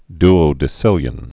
(dō-dĭ-sĭlyən, dy-)